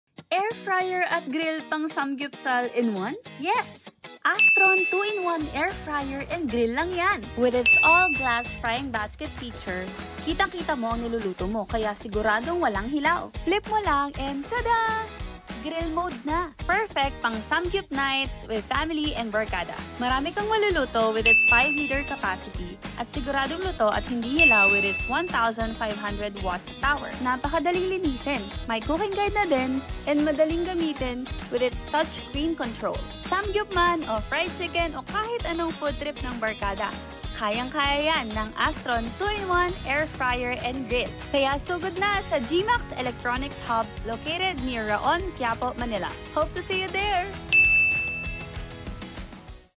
🔥 Air fryer + grill sound effects free download